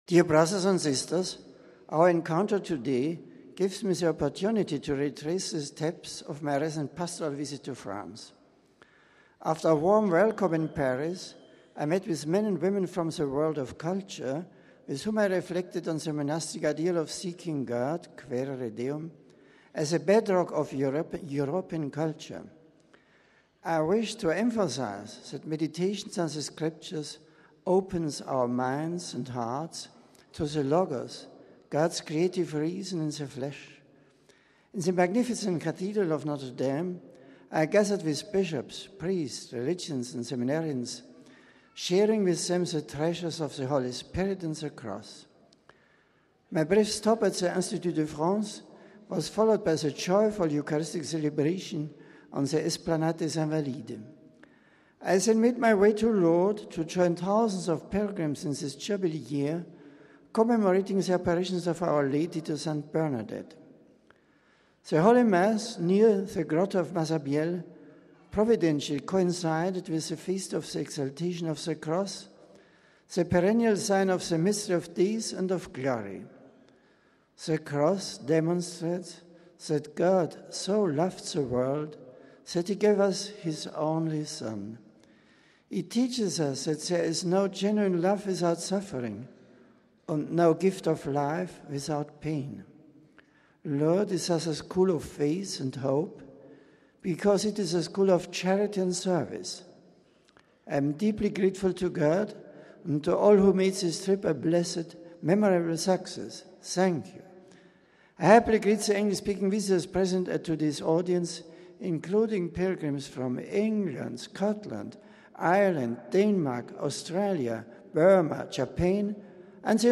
Pope Benedict in English - Weekly General Audience